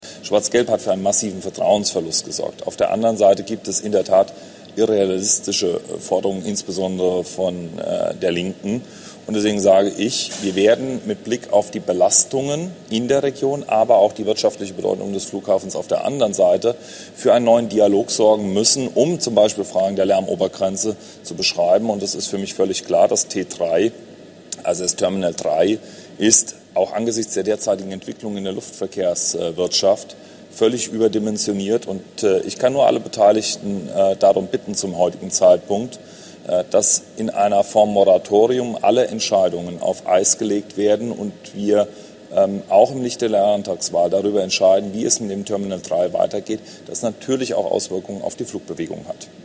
TSG_Sommerinterview_Flughafen.mp3